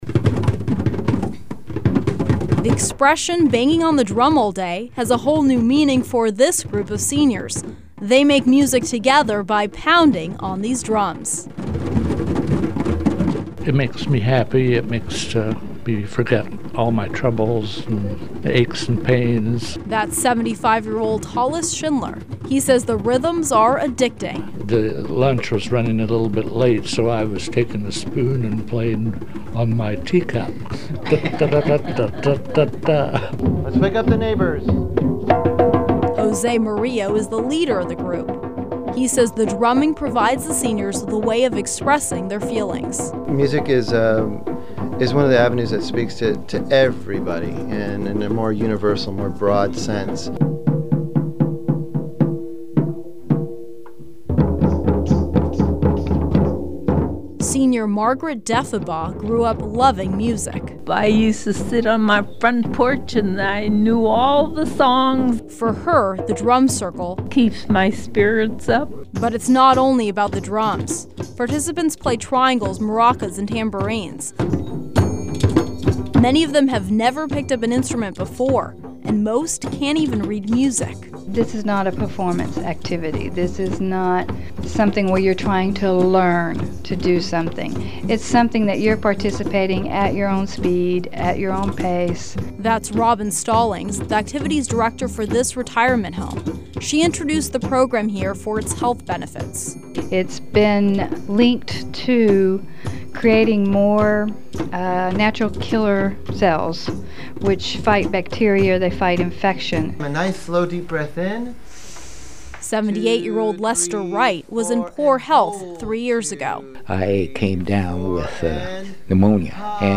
Drumming is not only an activity for the young -- senior citizens who live at Bethany Towers, a retirement home in Hollywood, have their very own drum circle.
DrumCircle_DG.mp3